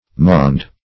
maund - definition of maund - synonyms, pronunciation, spelling from Free Dictionary